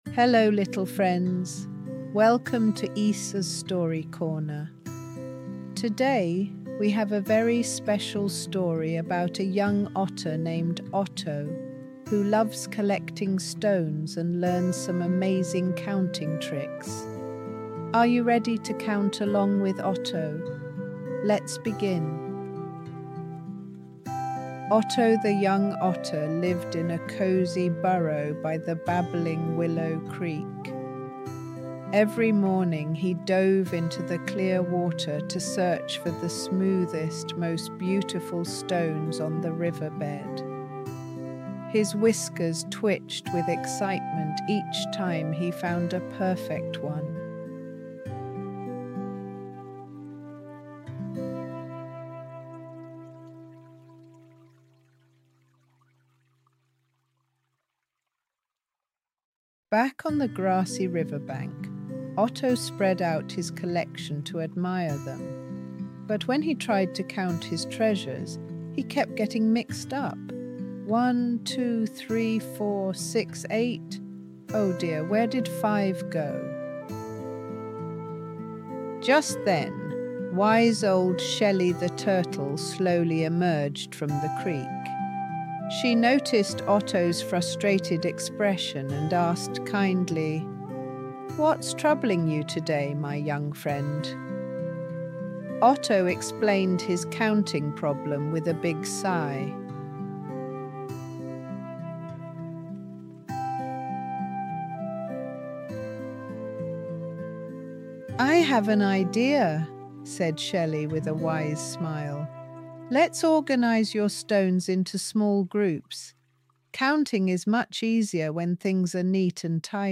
This gentle bedtime story follows young Otto as he learns to count by organizing stones into groups with wise Shelly the turtle.
Audio Story